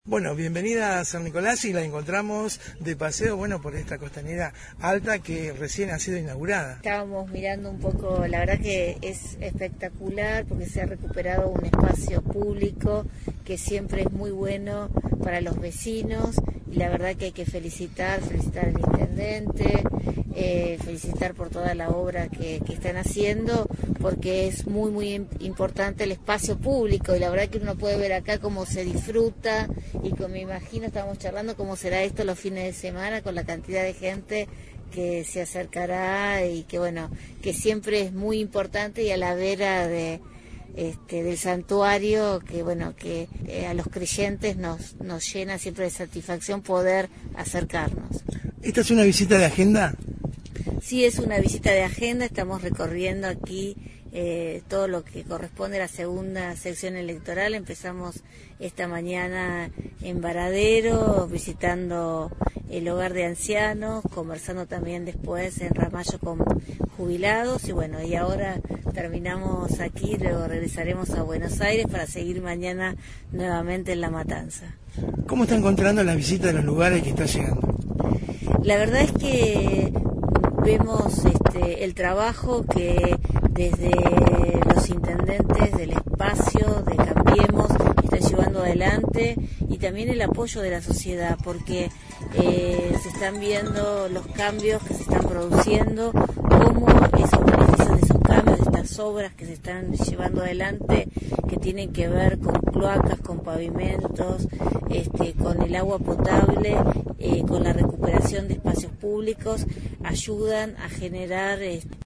VISIÓN EMPRESARIA en una nota exclusiva con Graciela.